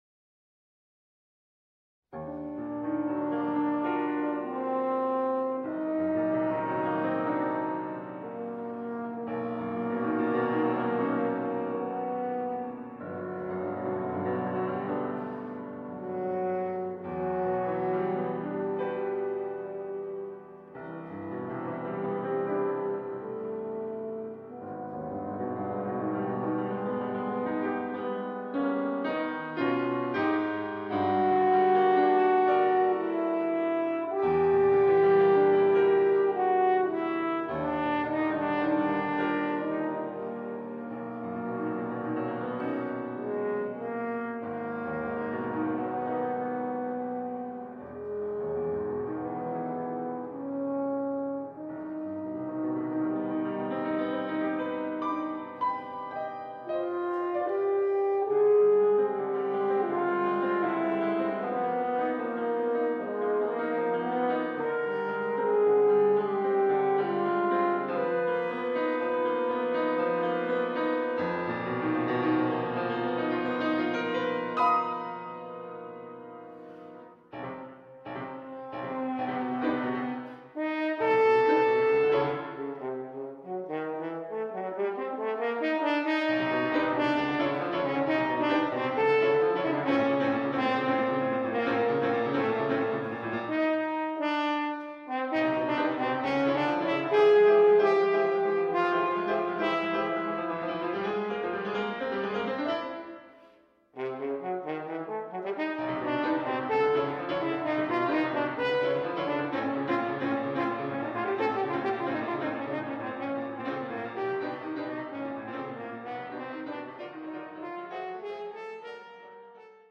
for French Horn and Piano